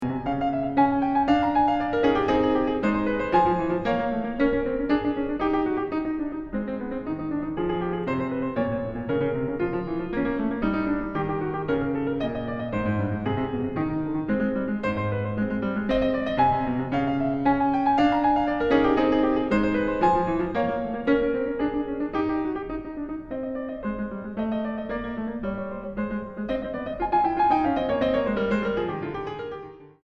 Música mexicana para piano de los siglos XX y XXI.
piano